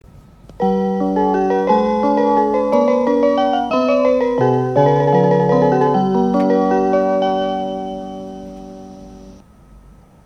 おなじみの新幹線の車内チャイムです。